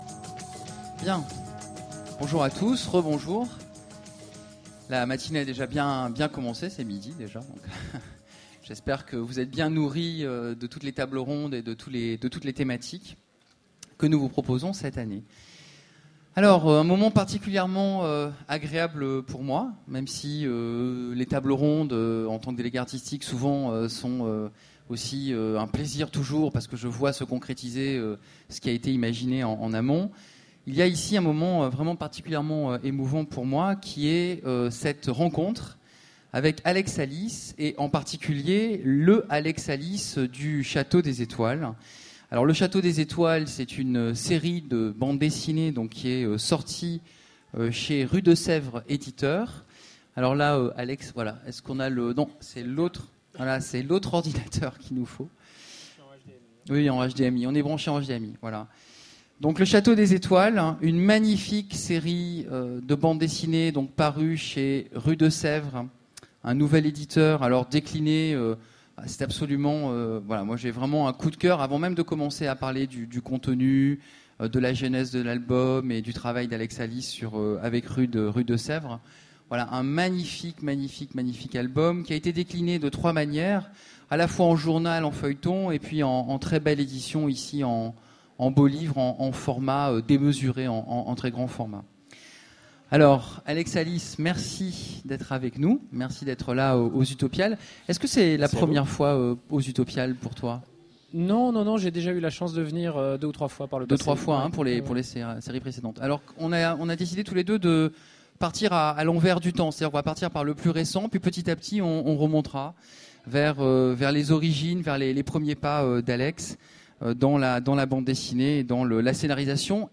Utopiales 2014 : Rencontre avec Alex Alice